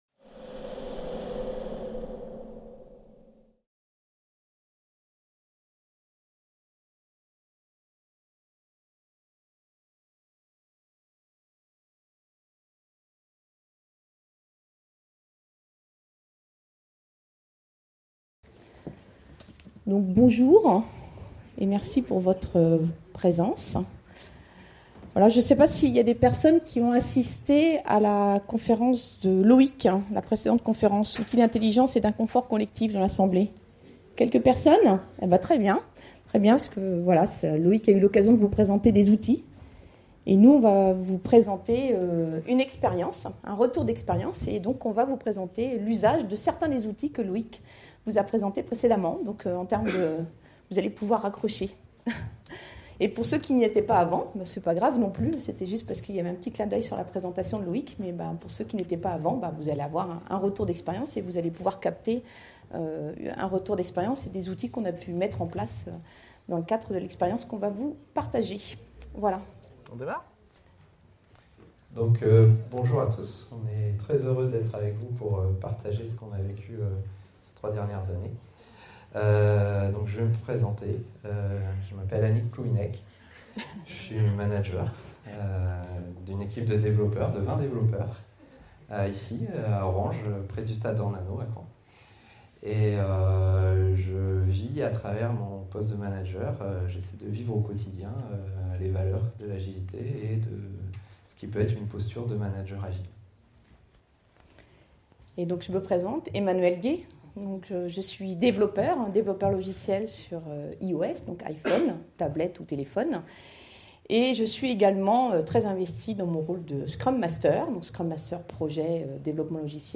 La conférence : Venez découvrir l’histoire d’une équipe de 50 personnes qui a fait le choix de ne pas remplacer son responsable.